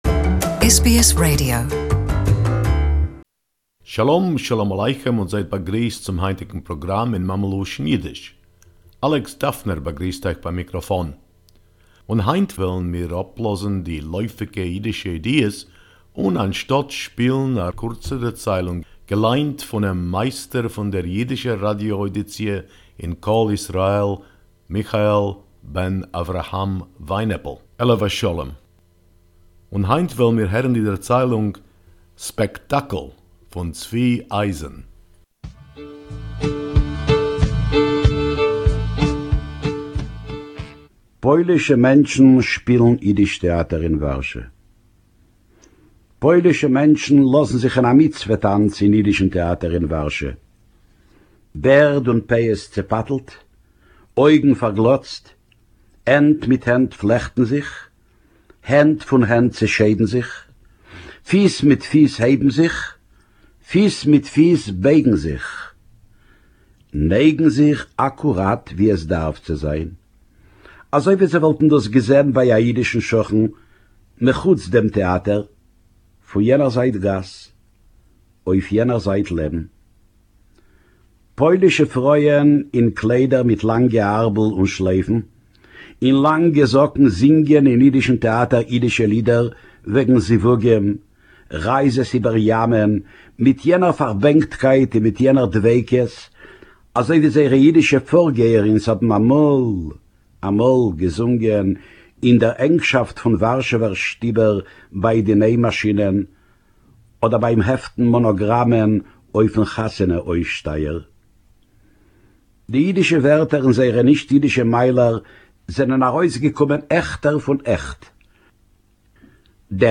Yiddish Story: "Spectacle” by Tzvi Ayzen